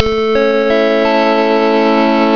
Crash Sounds:
Crash_Mac_IIfx .......................Macintosh IIfx